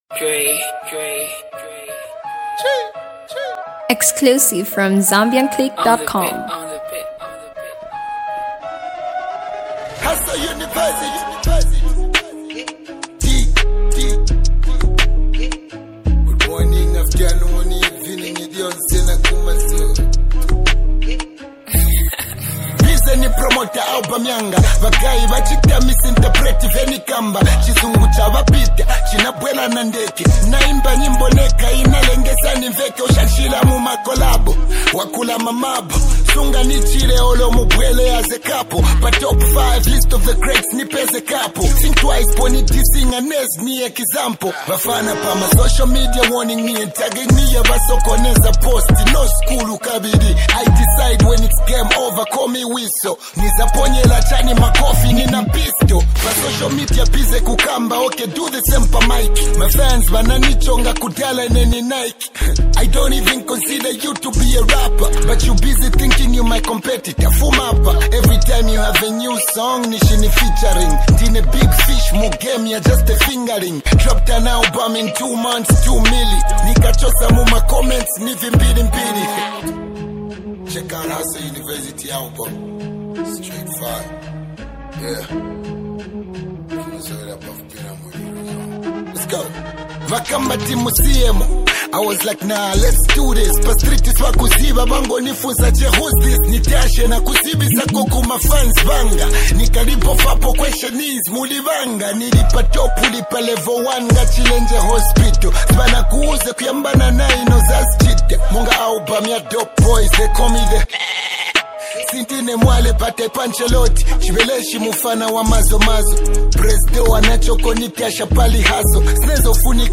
hip-hop diss song